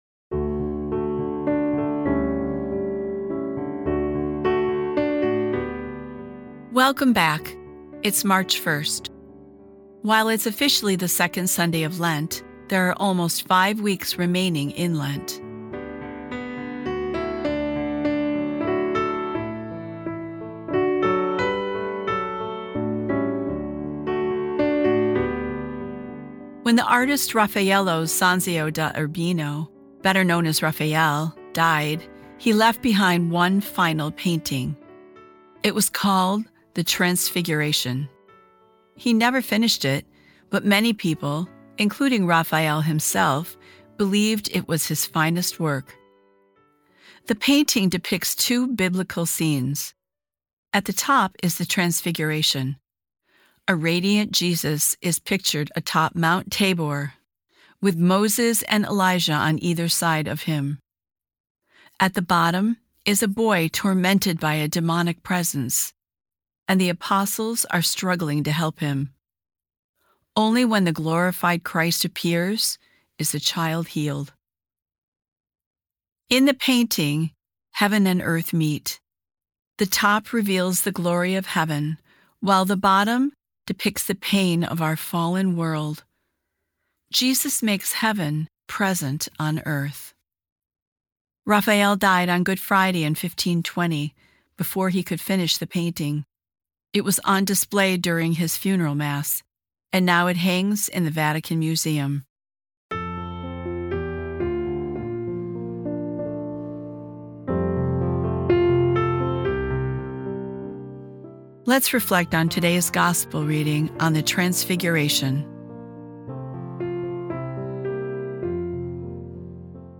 Today's episode of Sundays with Bishop Ken is a reading from The Little Black Book: Lent 2026.